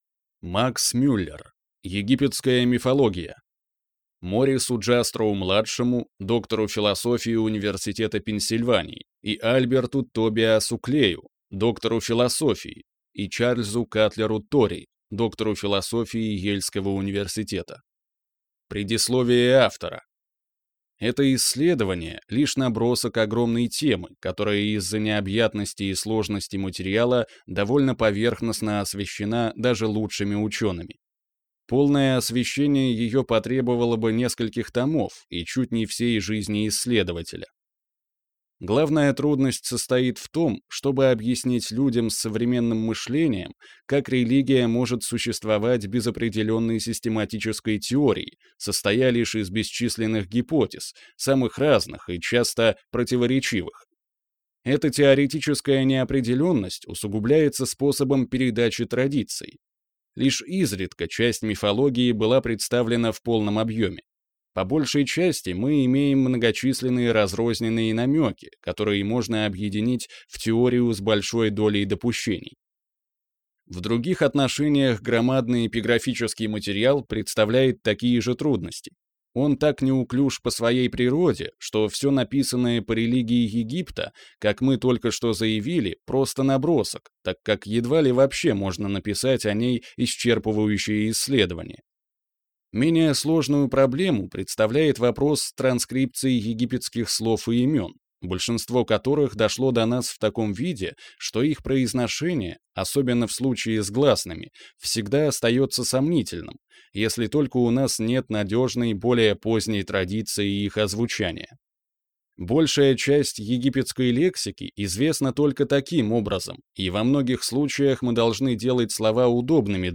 Аудиокнига Египетская мифология | Библиотека аудиокниг